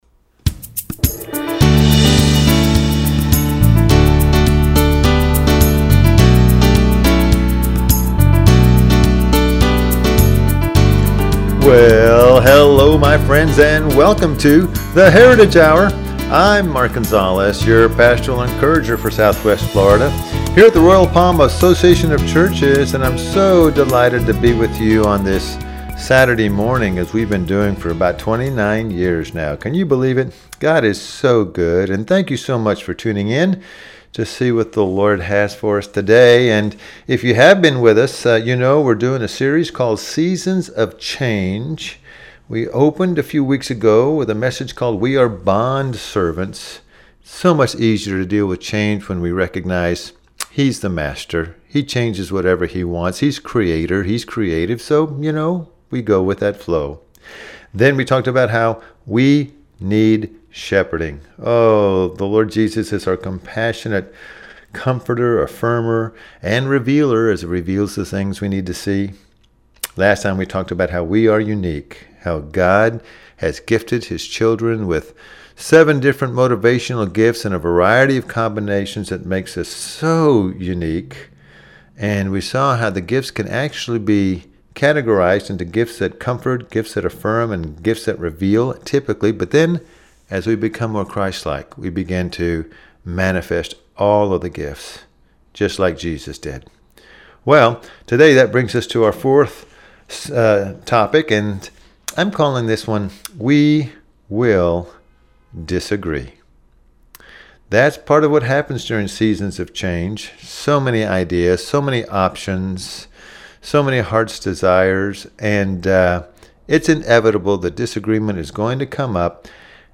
Service Type: Radio Message